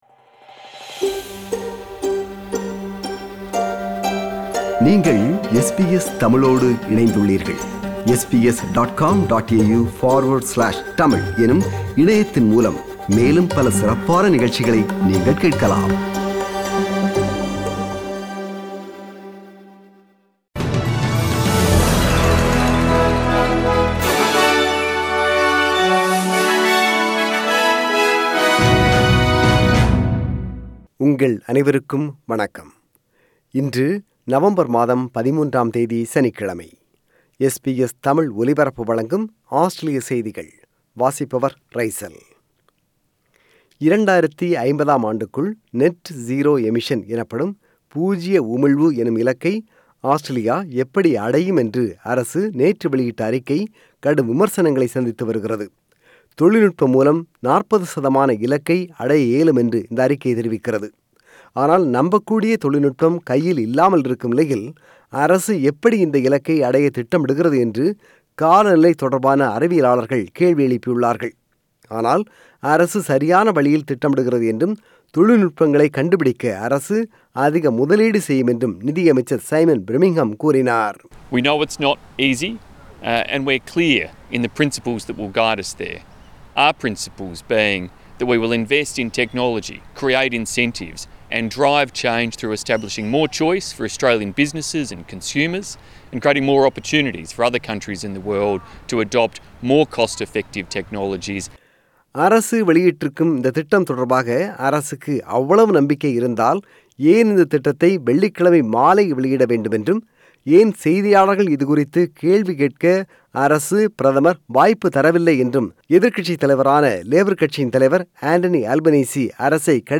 Australian News: 13 November 2021 – Saturday